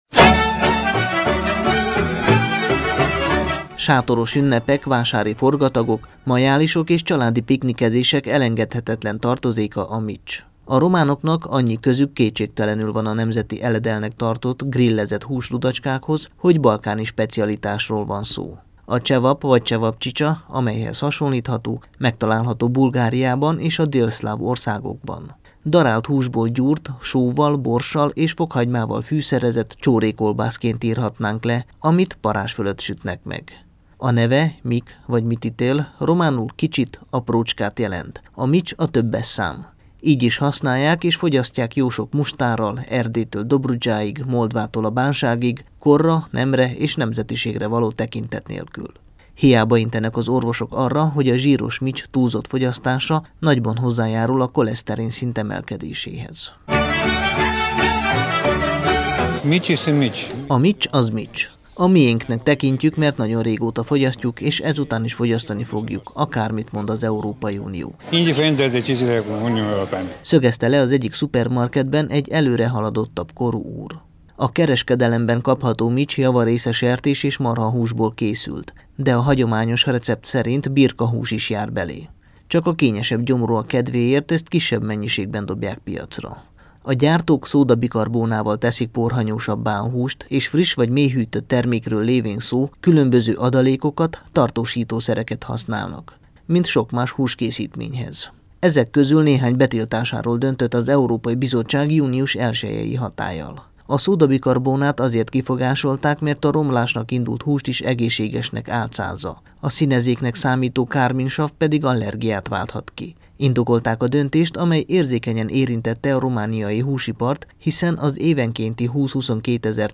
A „miccstilalom” felfüggesztéséről a Kossuth Rádióban [AUDIÓ]